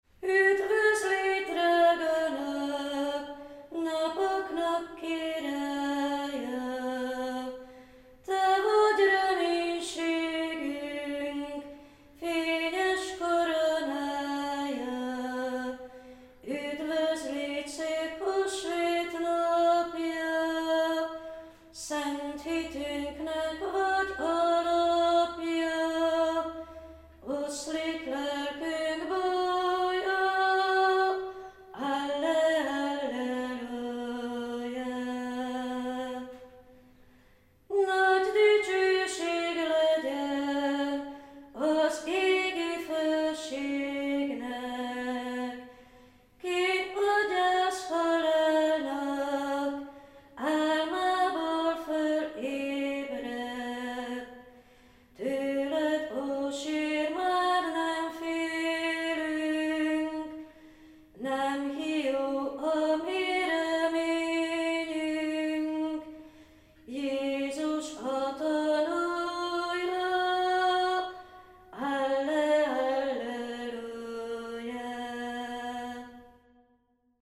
K�rp�t-medencei n�p�nekgy�jtem�ny